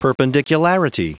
Prononciation du mot perpendicularity en anglais (fichier audio)
Prononciation du mot : perpendicularity
perpendicularity.wav